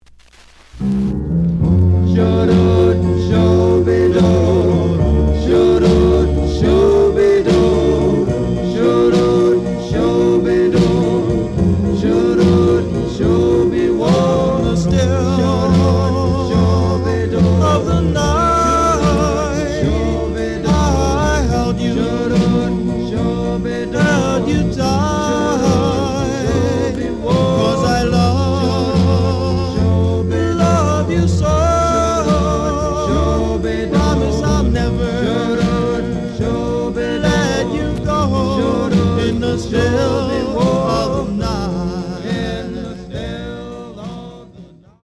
The audio sample is recorded from the actual item.
●Genre: Rhythm And Blues / Rock 'n' Roll
Some nose on beginning of A side.